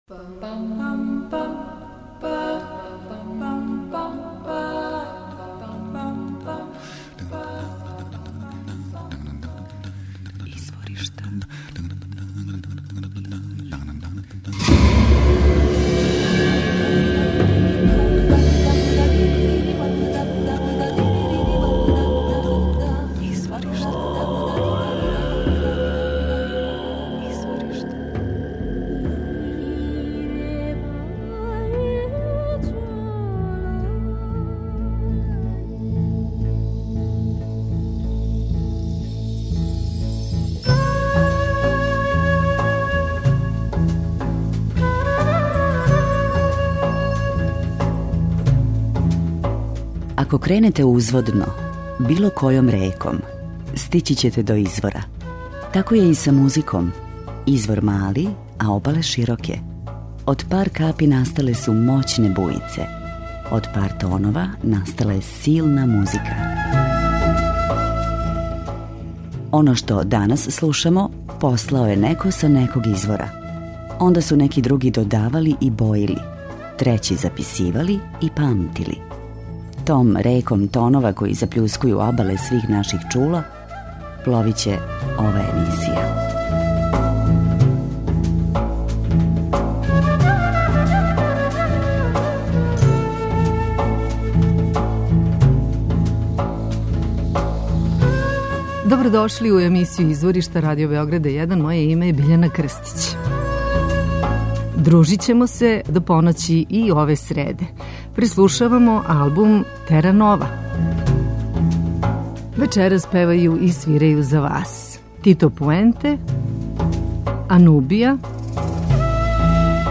омиљеног симбола латинског џеза